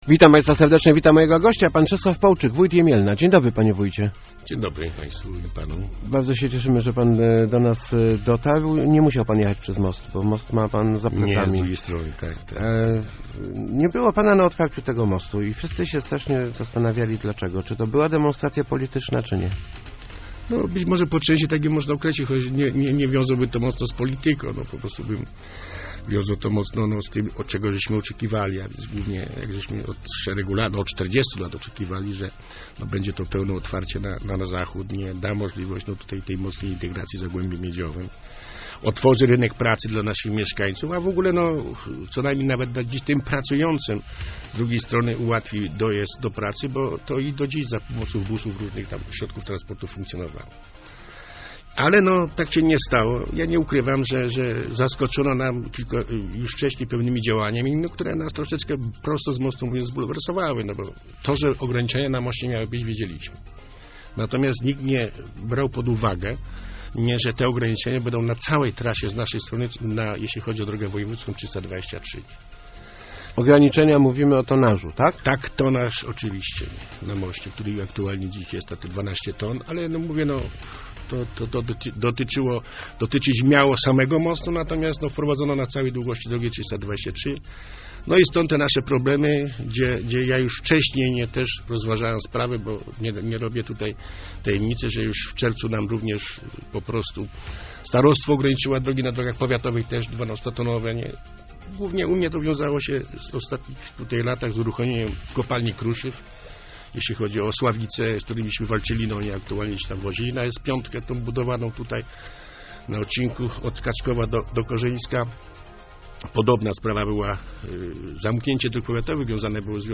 polczyk.jpgWybudowanie mostu w Ciechanowie ma pewne plusy, ale zdecydowanie więcej jest minusów - mówił w Rozmowach Elki Czesław Połczyk, wójt Jemielna. Problemem jest zwłaszcza ograniczenie tonażu na drogach do 12 ton - zdaniem wójta praktycznie paralizuje to rozwój gospodarczy gminy.